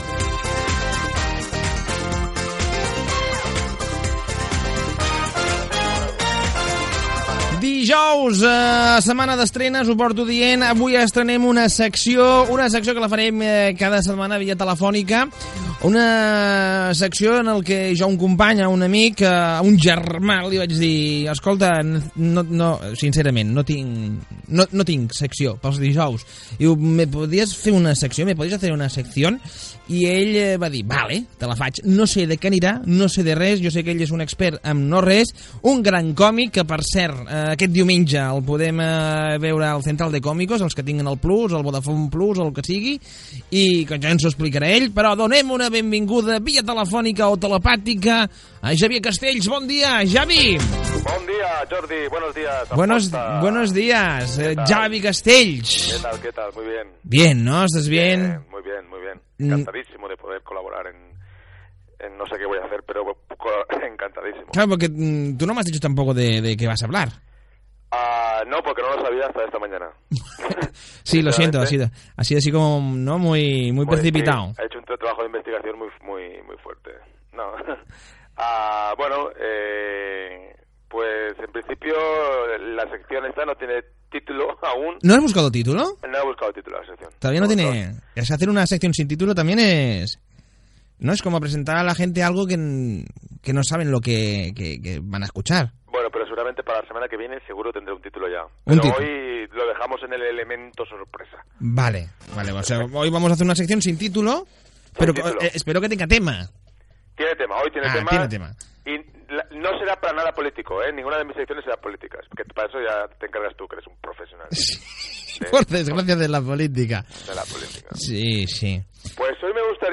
Secció dedicada a la dona que ha tingut més fills Gènere radiofònic Entreteniment